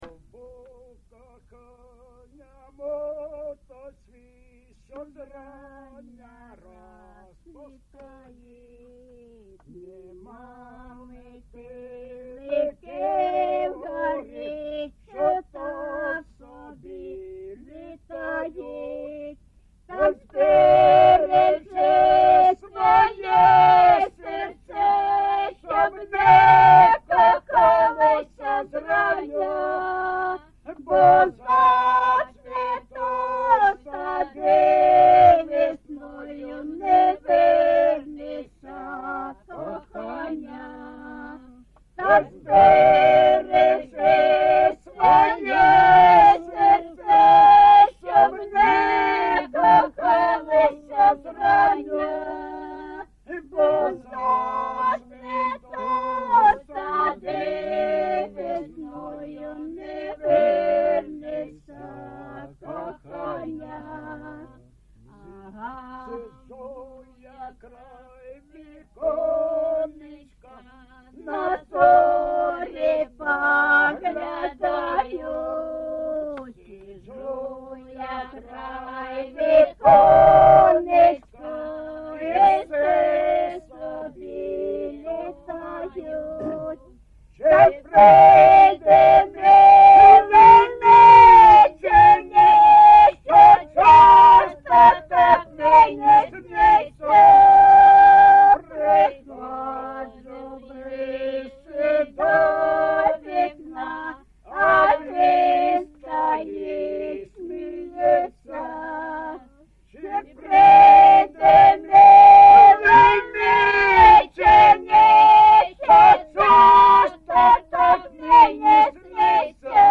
ЖанрПісні з особистого та родинного життя, Романси, Сучасні пісні та новотвори
Місце записум. Дружківка, Краматорський район, Донецька обл., Україна, Слобожанщина